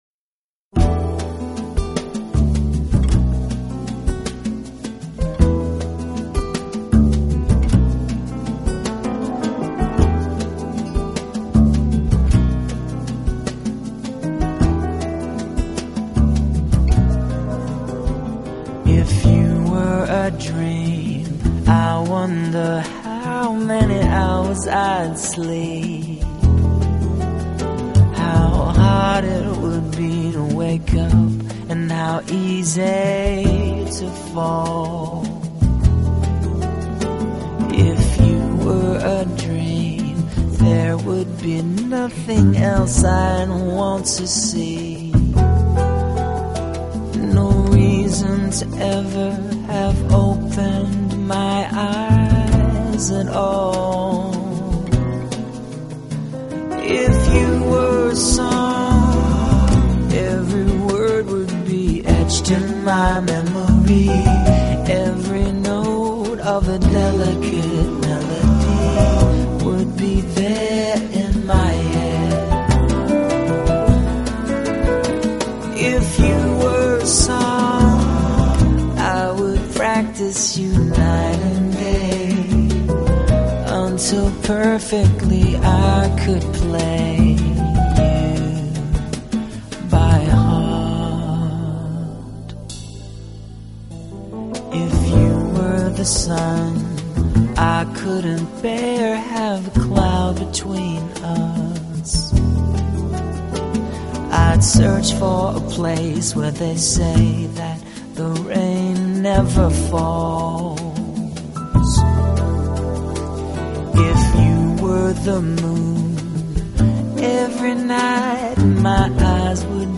【爵士乐系列】